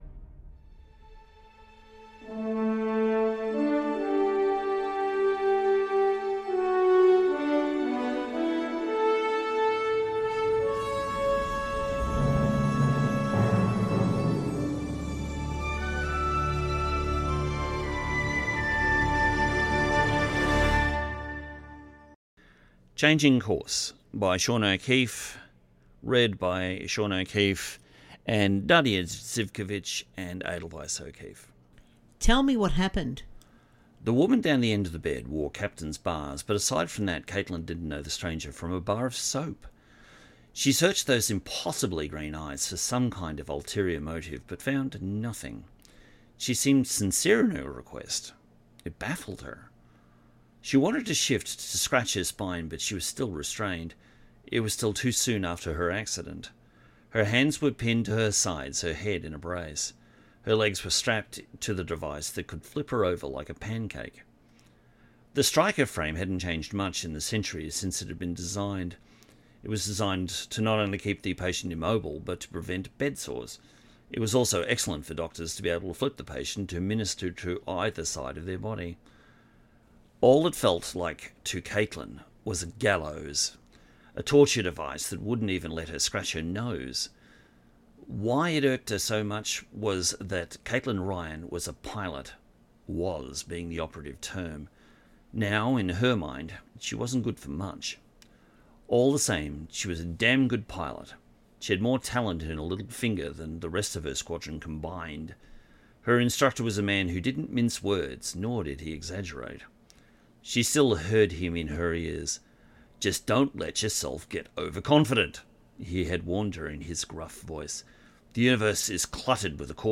Audio Books/Drama
Changing-Course-with-title-music.mp3